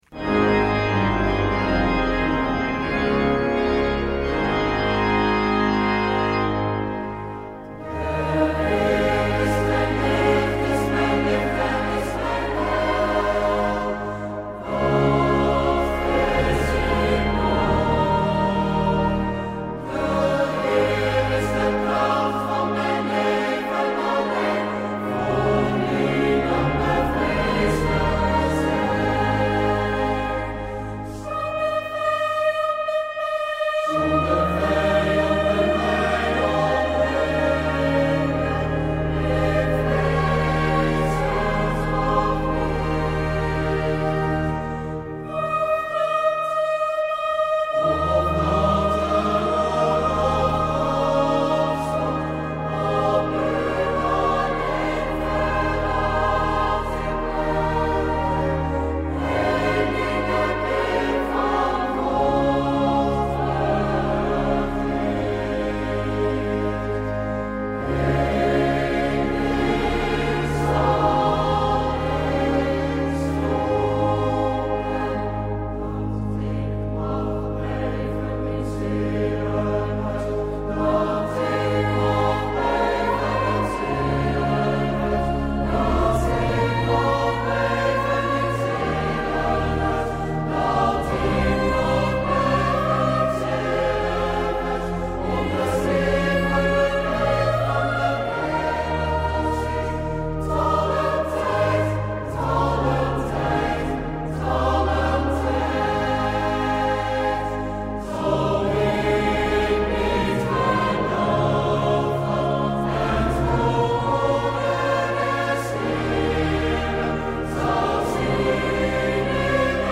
Opening van deze zondag met muziek, rechtstreeks vanuit onze studio.